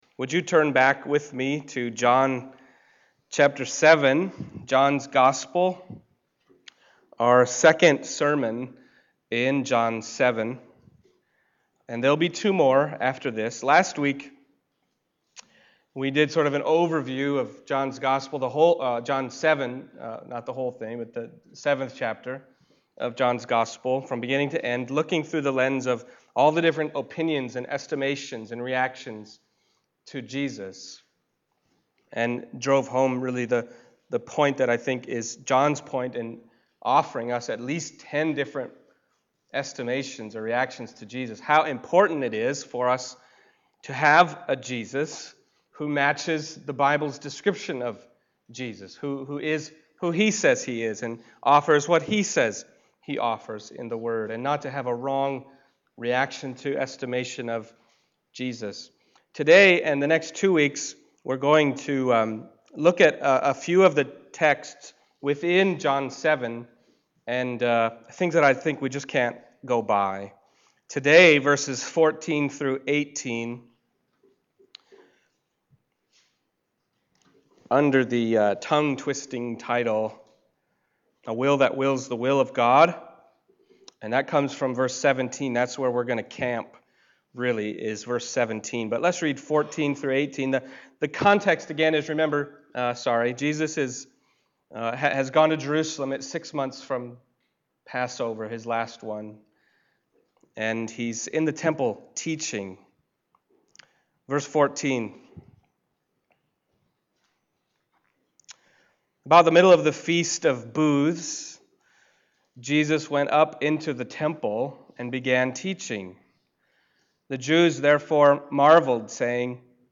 John Passage: John 7:14-18 Service Type: Sunday Morning John 7:14-18 « Who Do You Say That I Am?